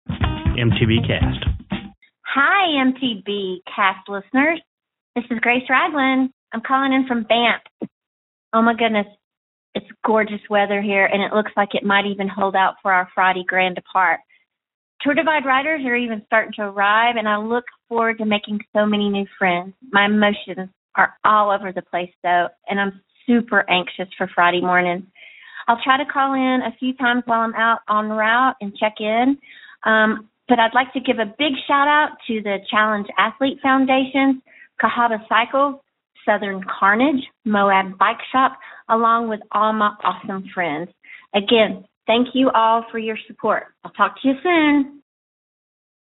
Posted in Calls , TD18 Tagged bikepacking , cycling , MTBCast , TD18 , ultrasport permalink